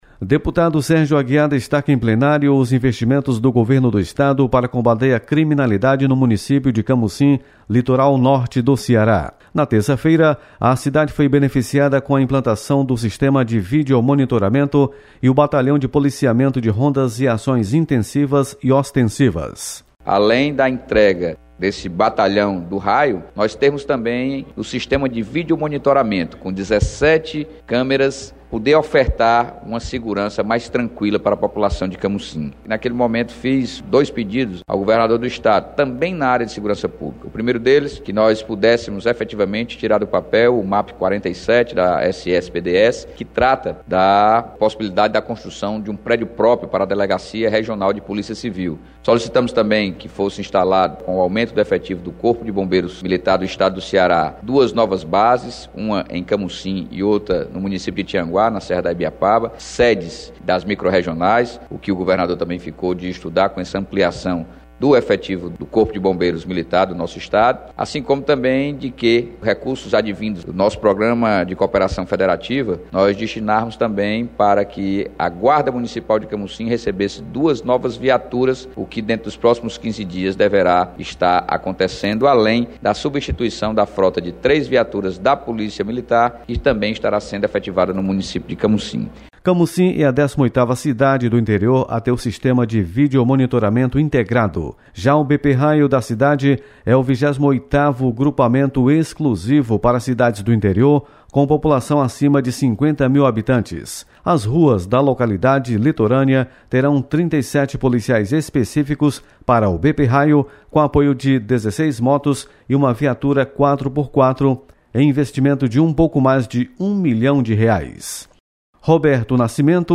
Deputado Sérgio Aguiar destaca investimentos na segurança em Camocim. Repórter